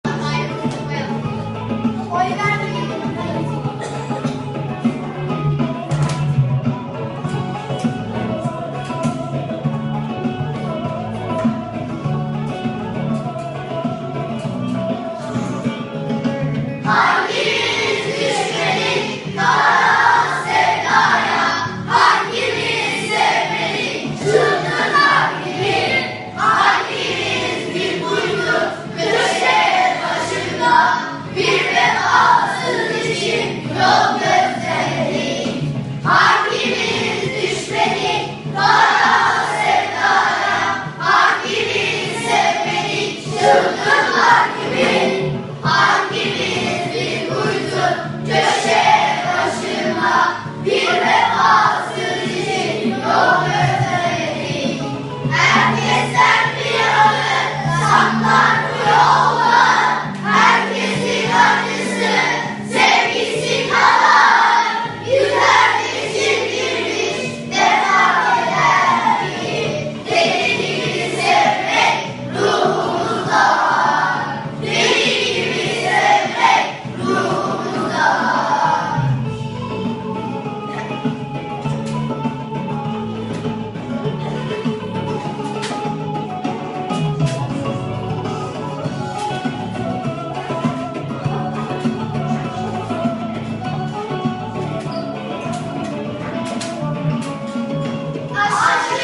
Giho Şarkı Yarışması Albümü